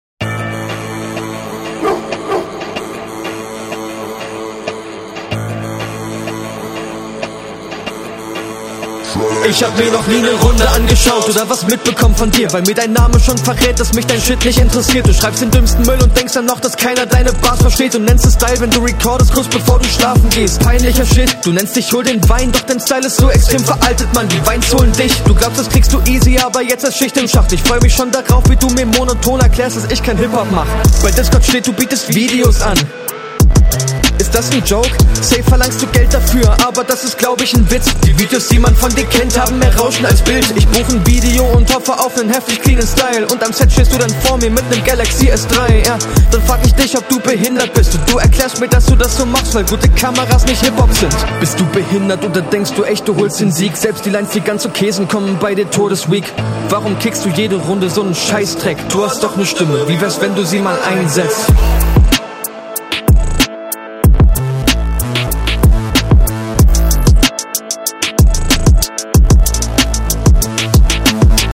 hook sehr nice, einige konter leider sehr flach, wirkt oft wie die erstbeste option und …
Das ist stabil gerappt, aber iwie passt es so null zum beat, auf so n …